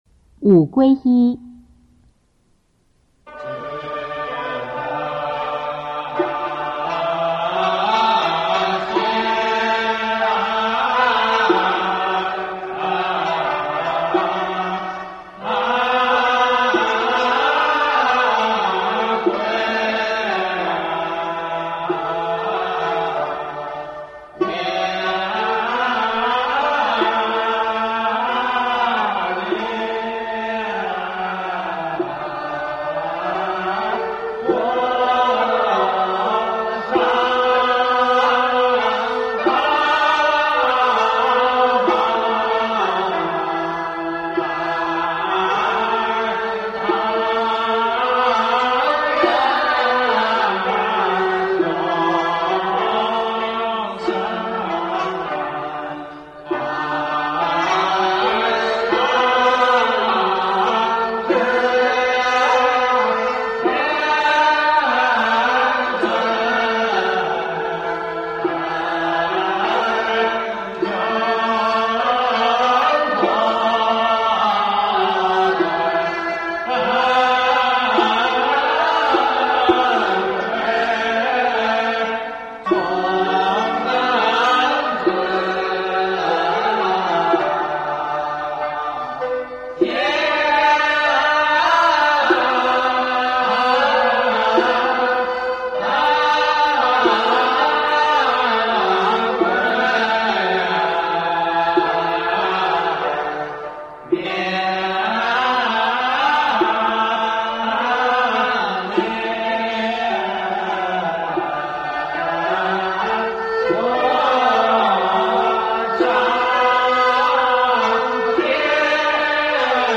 中国道教音乐 全真正韵 午皈依